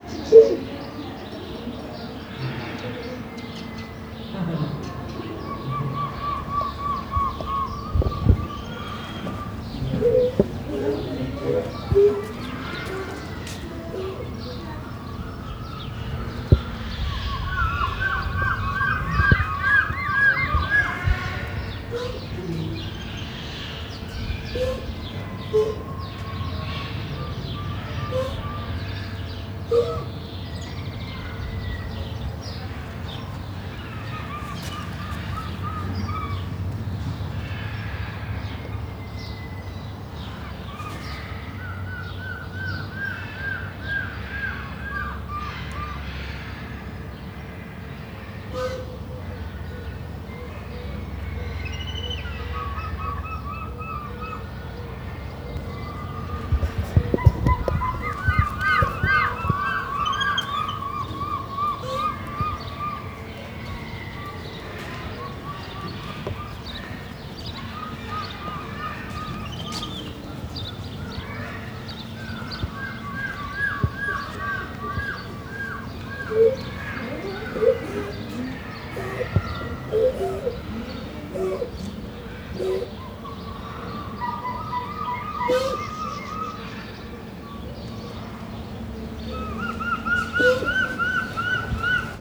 szentibisz01.32.wav